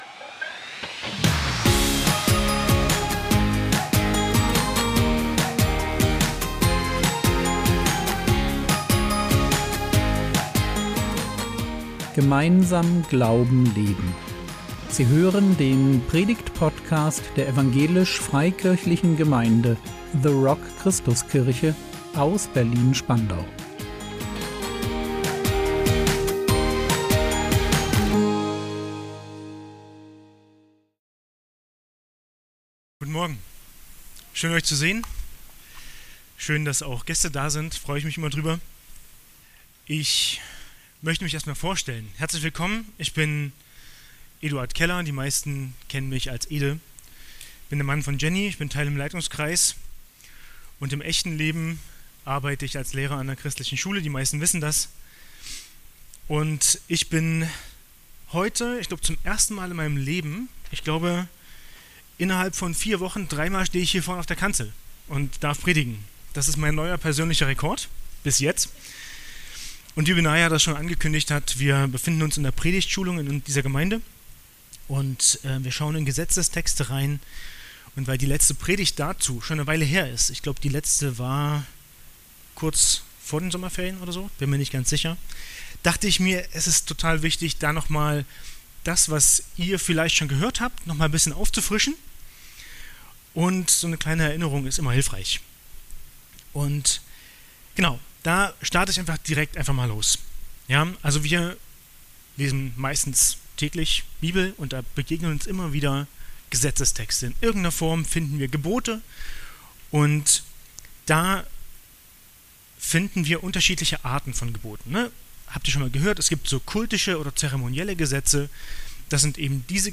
Ehrliches Herz oder leere Hülle | 05.10.2025 ~ Predigt Podcast der EFG The Rock Christuskirche Berlin Podcast
Predigtschulung - 5.